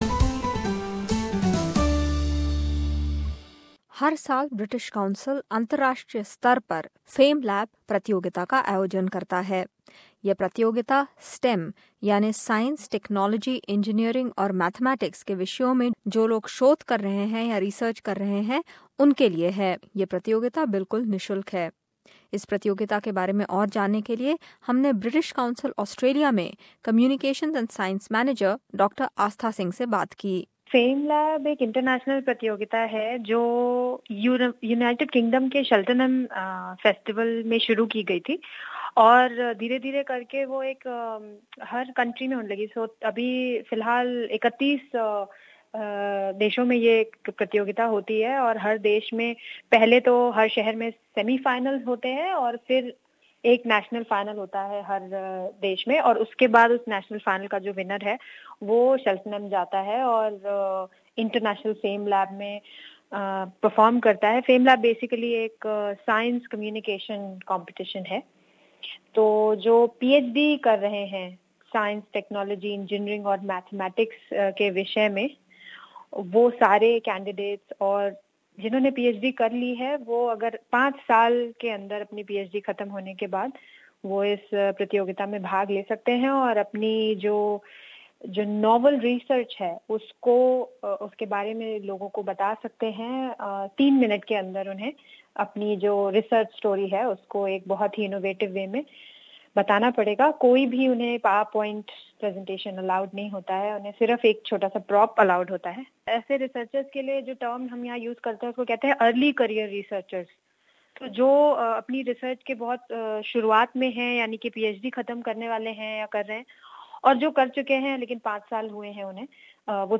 Have you heard of FameLab? If not tune in to this report.